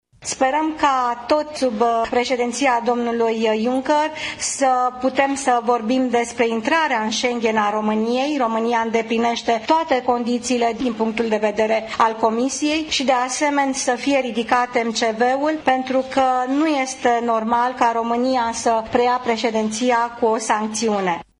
Precizările au fost făcute miercuri seară, la Bruxelles, într-o conferinţă de presă comună cu premierul Viorica Dăncilă.